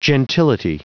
Prononciation du mot gentility en anglais (fichier audio)
Prononciation du mot : gentility